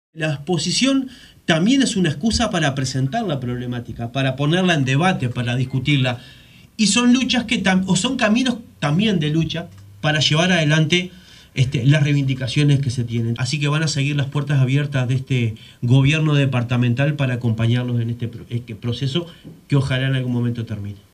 Pro Secretario General de la Intendencia de Canelones, Marcelo Metediera, hace uso de la palabra